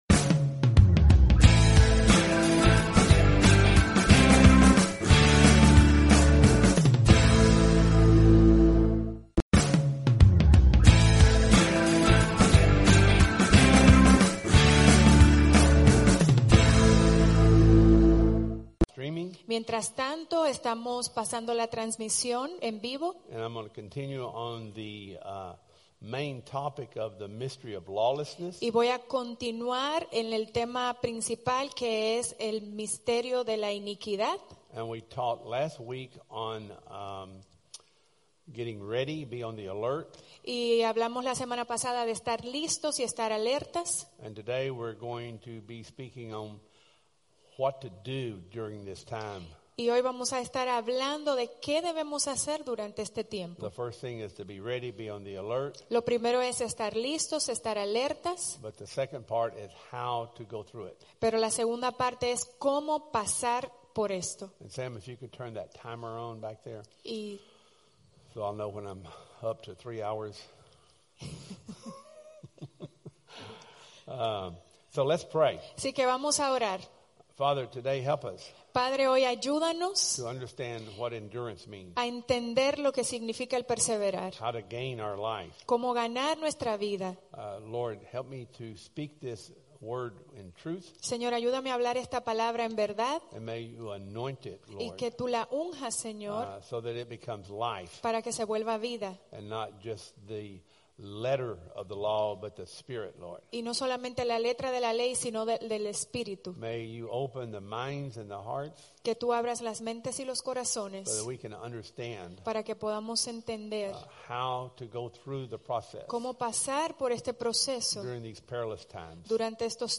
Sunday Services 16-20 Service Type: Sunday Service « Servants By Your Endurance